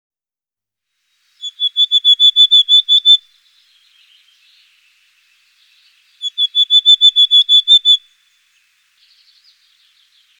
anhören), wogegen der Gesang der Alpenmeise aus meist kurzen, gleich hoch bleibenden Pfeiftönen besteht («dü dü dü dü dü», Sonogramm oben, Beispiel
Mesangeborealealpestrecopie.mp3